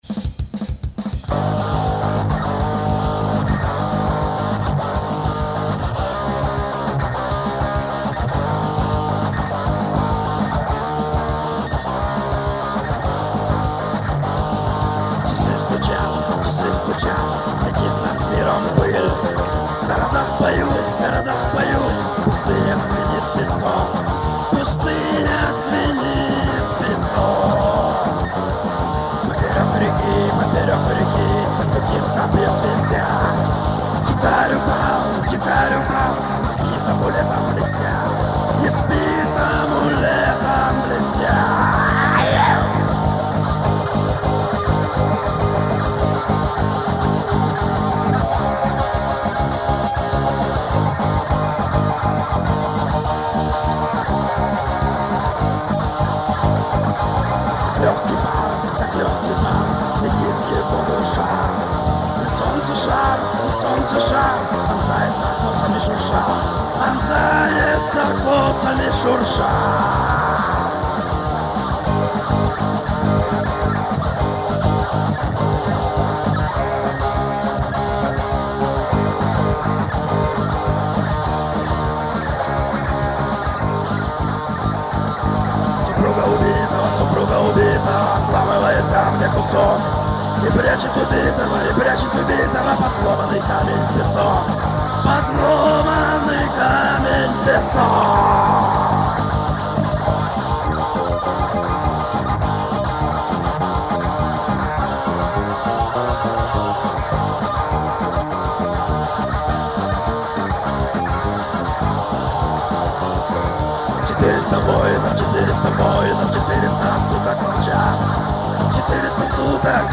Классический рок-н-ролл
на более-менее сносной аппаратуре осенью 1994-го года
вокал
гитара
клавиши
бас
Русский рок